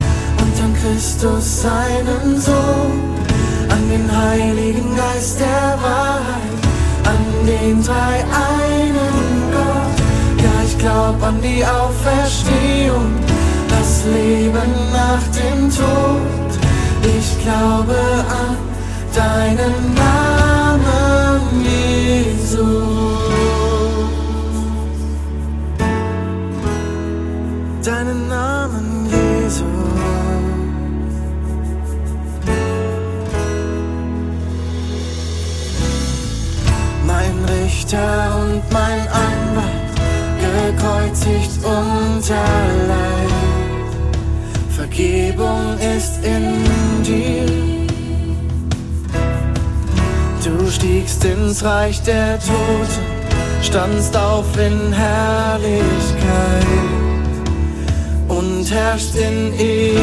Text) Worship 0,99 €